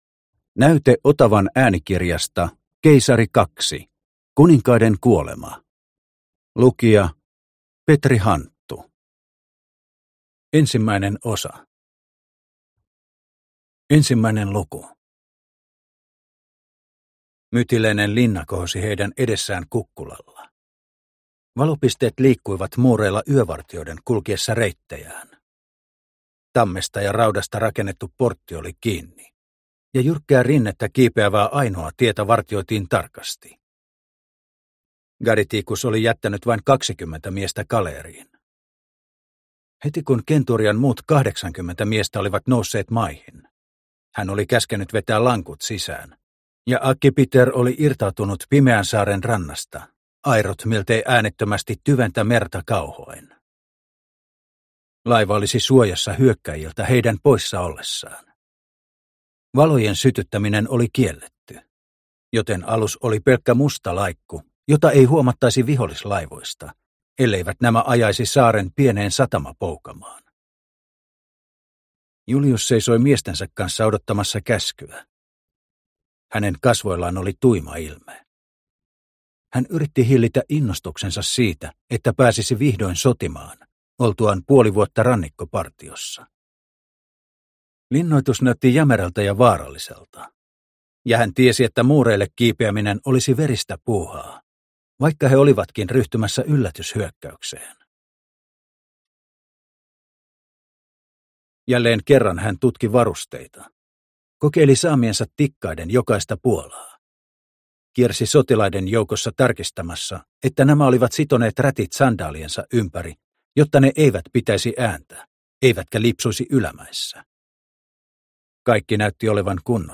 Keisari II Kuninkaiden kuolema – Ljudbok – Laddas ner